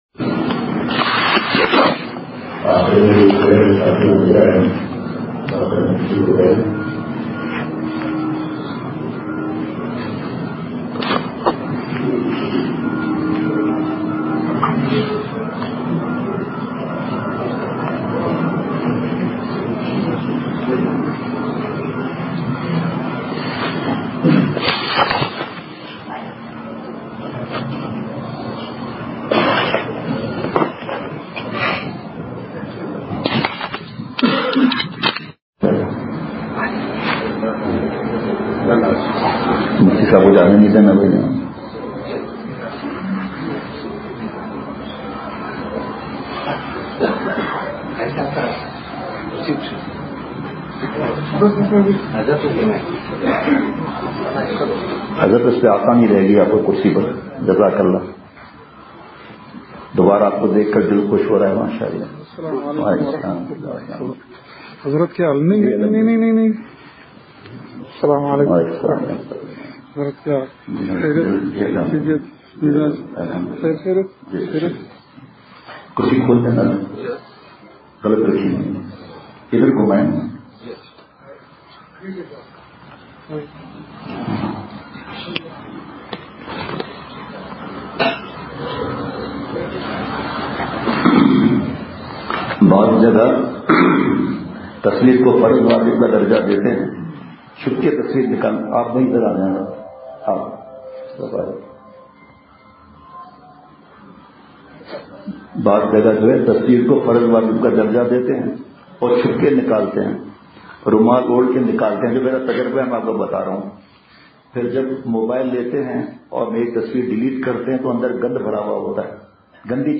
بمقام: مرکزی جامع مسجد پشین
نماز سے پہلے مسجد کا ہال بھر چکا تھا۔
درد بھری رقت آمیز دعا۔